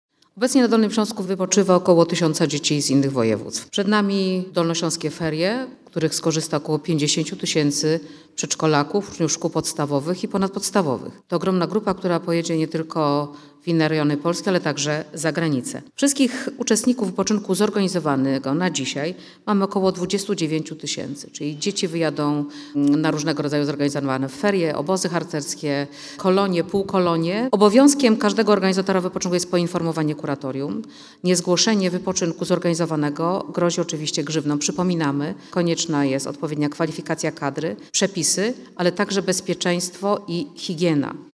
Dolnośląska Kurator Oświaty Ewa Skrzywanek przypomina o obowiązku zgłaszania miejsc zorganizowanego wypoczynku.